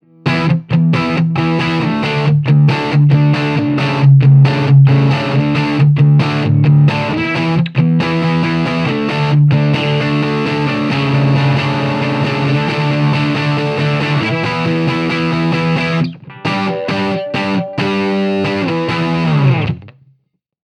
TC-15 DIRTY
Marshall G10
The TC-15 tone was the Ch.1 EF86, "Lo" input, "munch".
TC15_DIRTY_MarshallG10.mp3